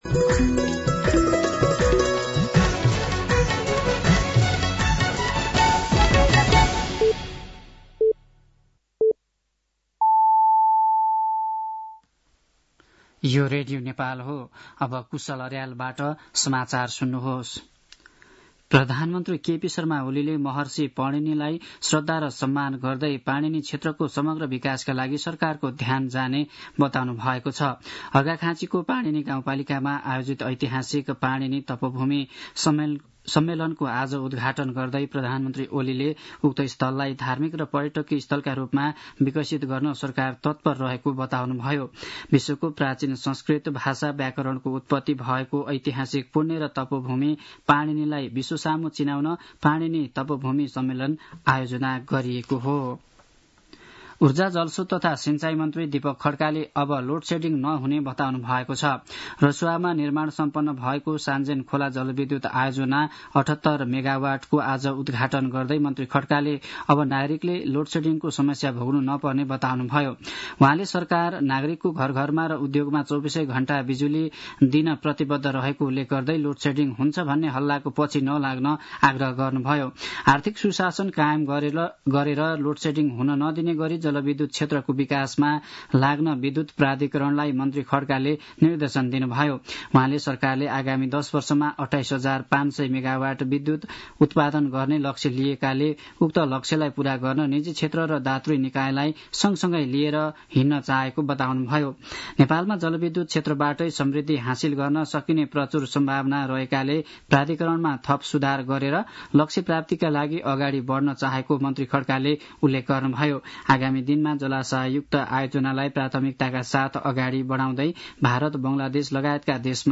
साँझ ५ बजेको नेपाली समाचार : २७ चैत , २०८१